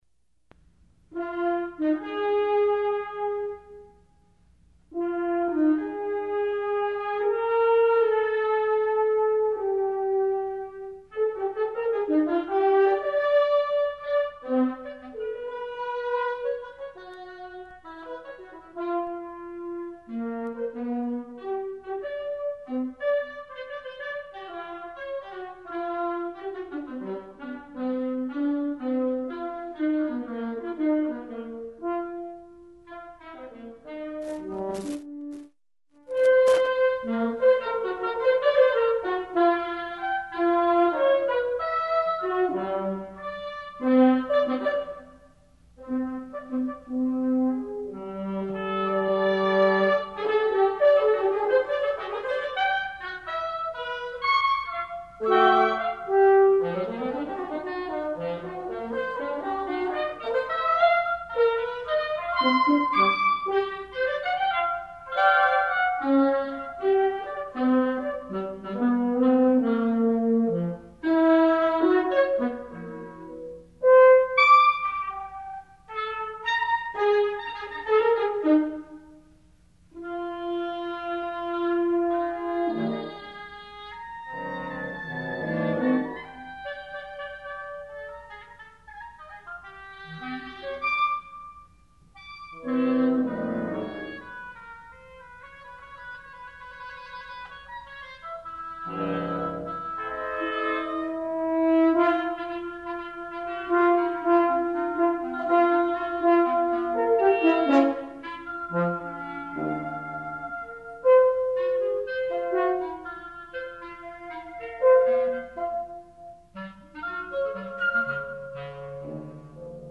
chamber composition